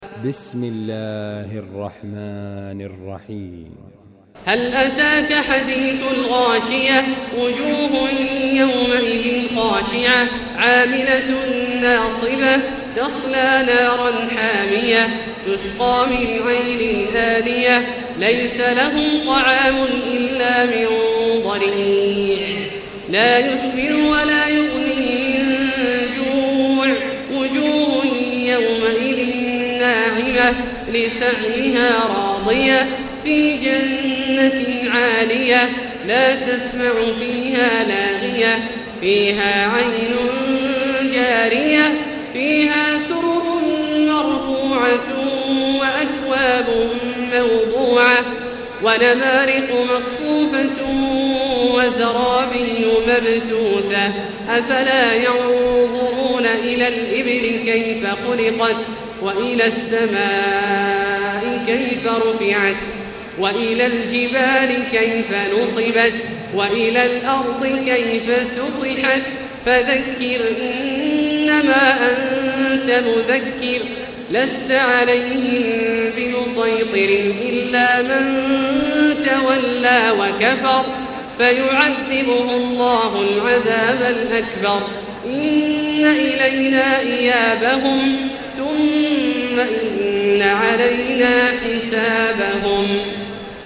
المكان: المسجد الحرام الشيخ: فضيلة الشيخ عبدالله الجهني فضيلة الشيخ عبدالله الجهني الغاشية